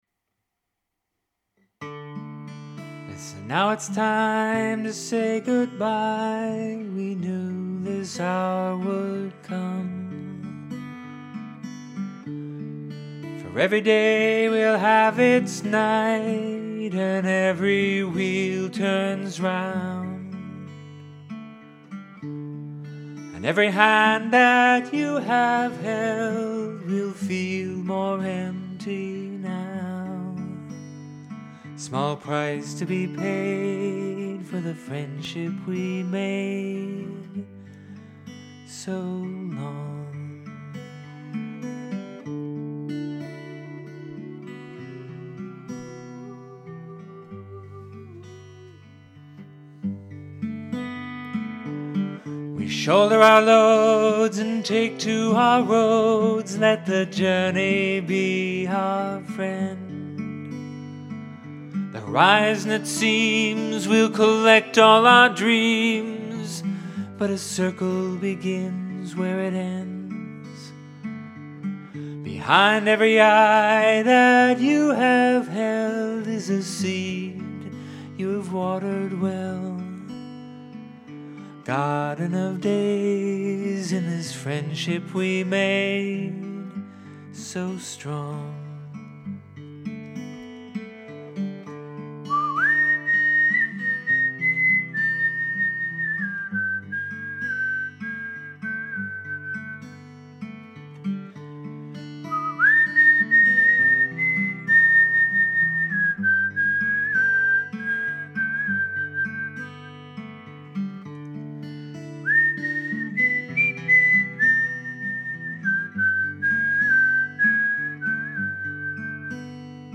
I wish I had had a space and the guitar to sing it to these friends, too; I guess this is the space, and here is my guitar.